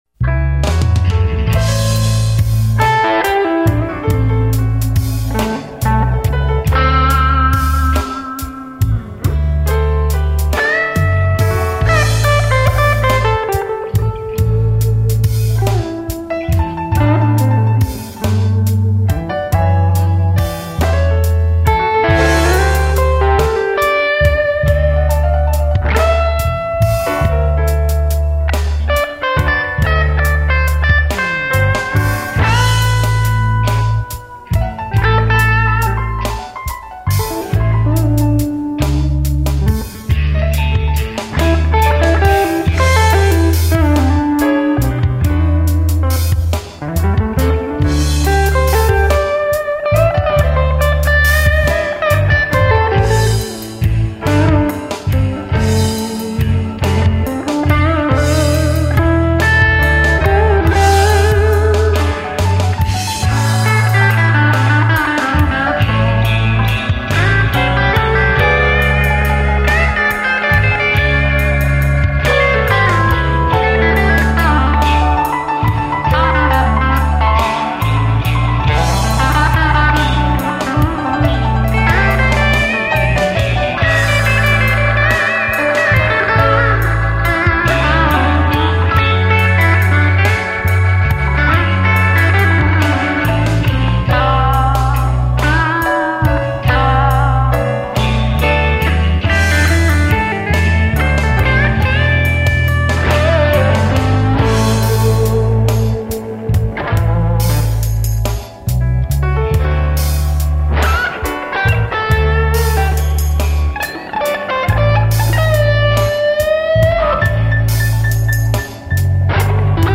Allerdings nicht mit dem JTM, sondern mit dem Pod und einer JTM-Simulation.
Hier also nun das Pedal im Einsatz, Tokai Paula, erst Hals-PU, dann Zwischenstellung (bei 1:03), dann schalte ich bei 1:43 den Overdrive dazu und wechsle gleichzeitig auf den Hals-PU zurück, nachher geht es dann auf den Bridge-PU.
Tube-Overdrive im Einsatz
tubeoverdrive.mp3